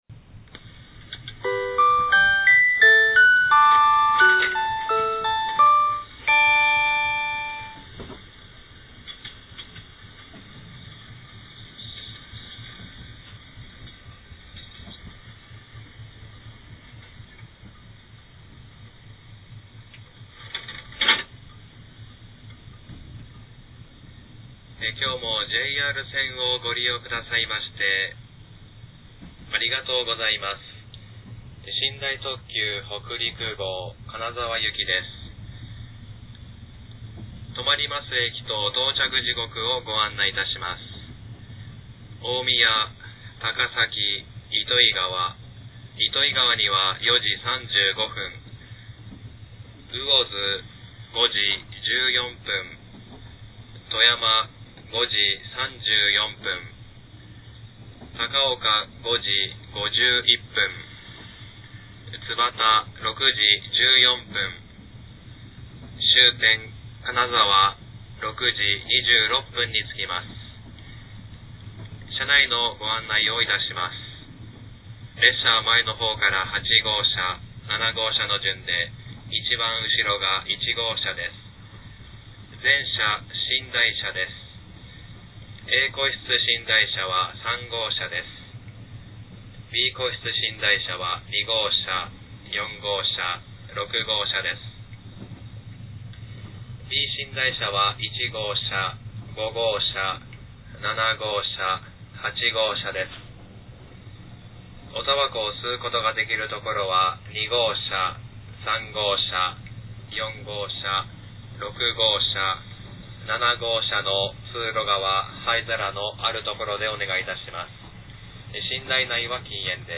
特別公開：下り寝台特急北陸号車内放送
●上野発車後
●大宮発車後